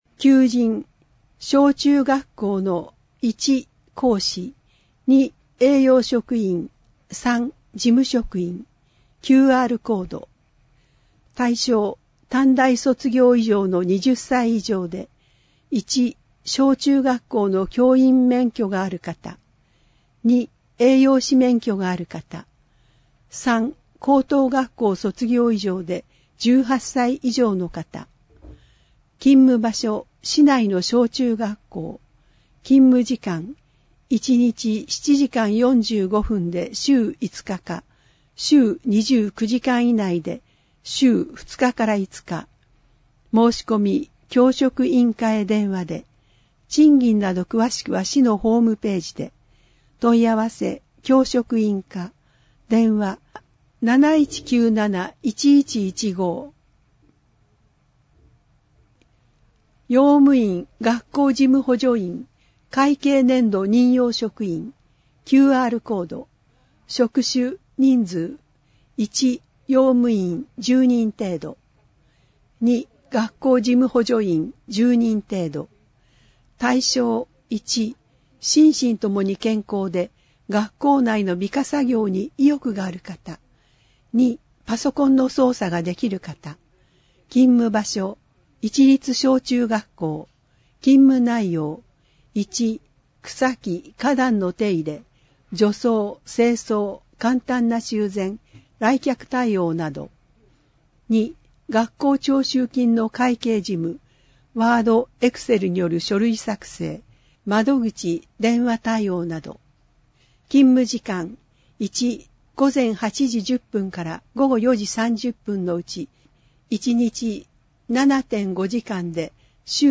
• 広報かしわの内容を音声で収録した「広報かしわ音訳版」を発行しています。
• 発行は、柏市朗読奉仕サークルにご協力いただき、毎号行っています。